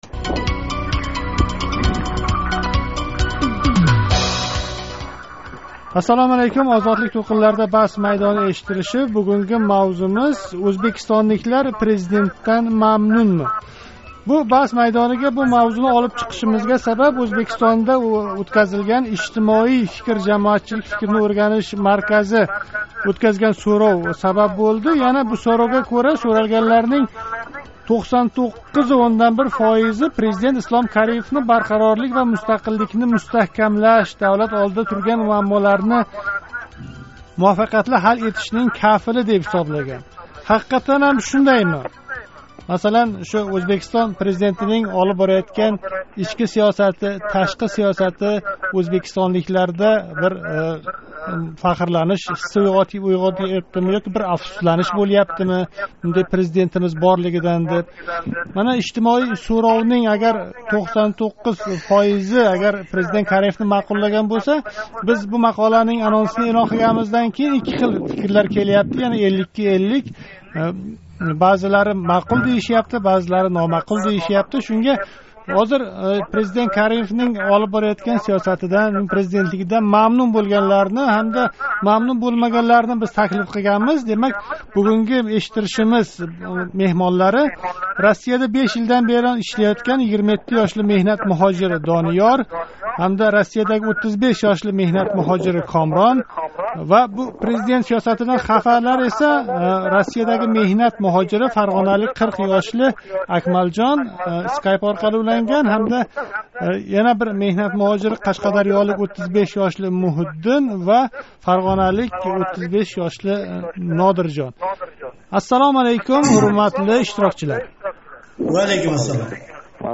Баҳс майдонига президент сиёсатидан "мамнун"ларни ва "мамнун бўлмаган"ларни таклиф этдик.